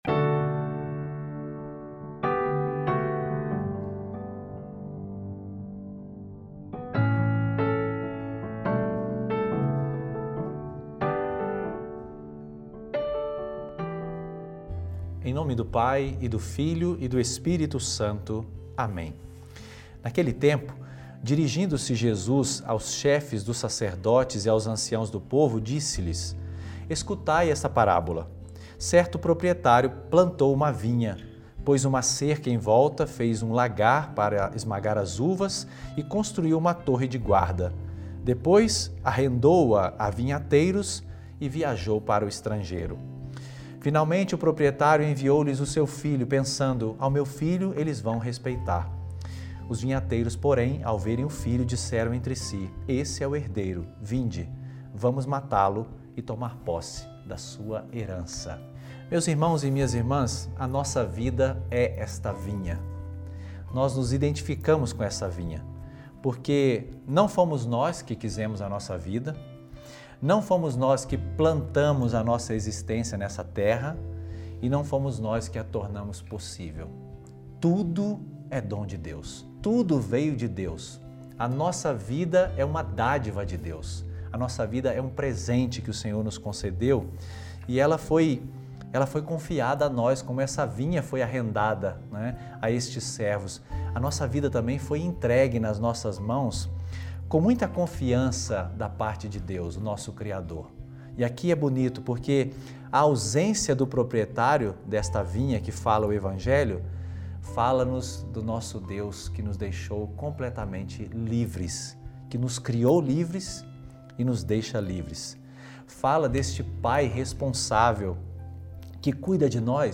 Homilia diária | A sua vida é uma dádiva de Deus